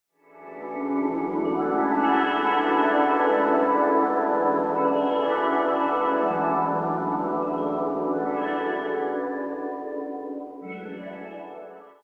Tag: 80 bpm Ambient Loops Fx Loops 2.02 MB wav Key : Unknown